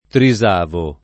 trisavolo [ tri @# volo ]